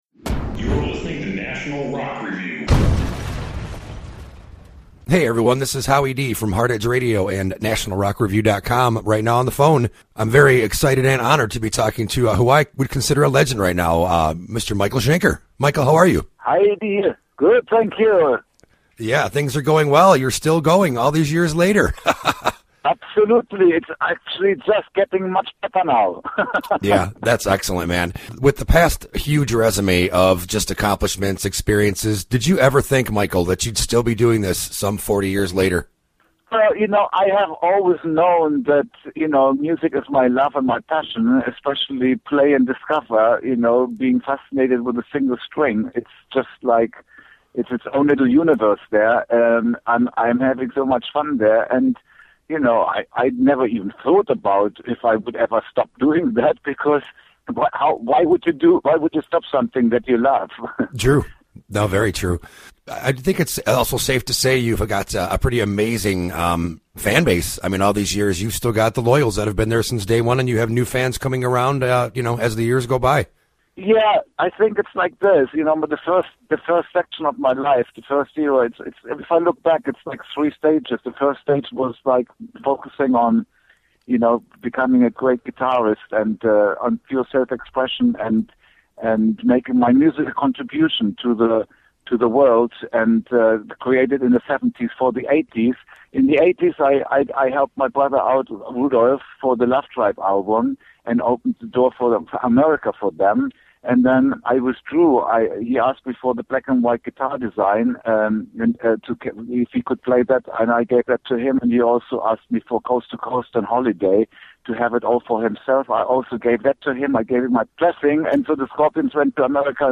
Please click below to hear the interview with Michael Schenker.